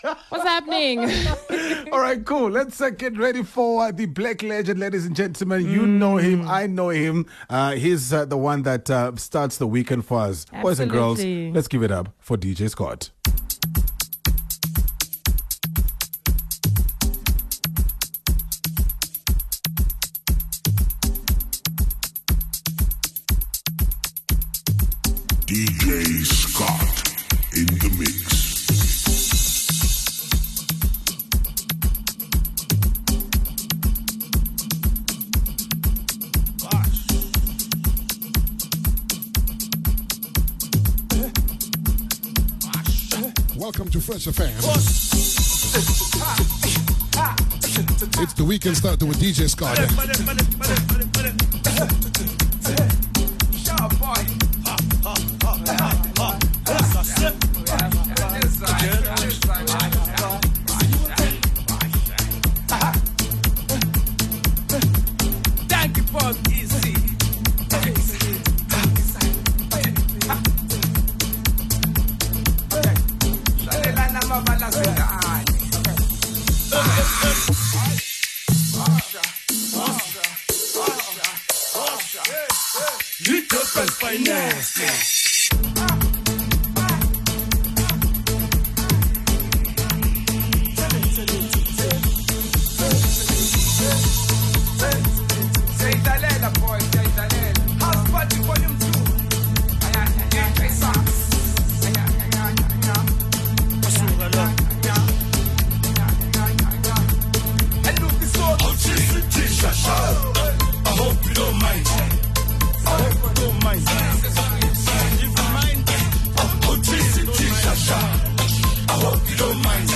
Today's edition of the Weekend Starter features classic house songs.